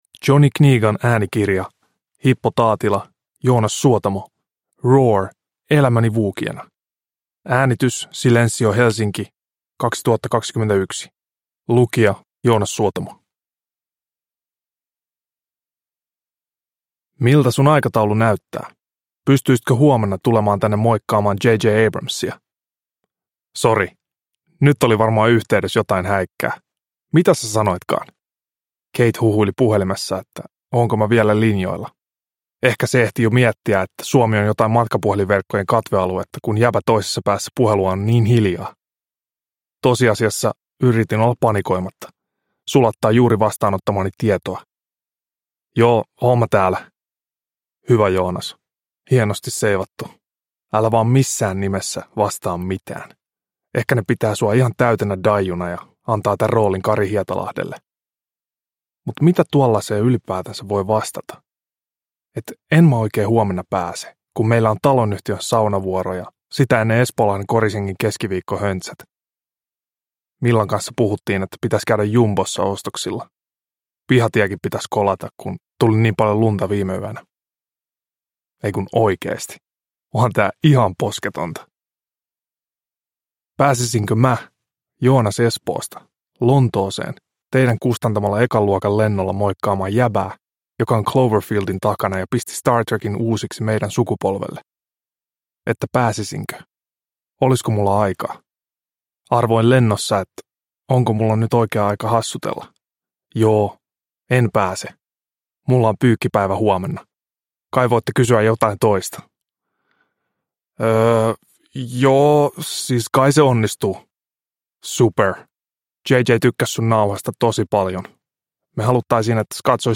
Roar! – Elämäni wookieena – Ljudbok – Laddas ner
Uppläsare: Joonas Suotamo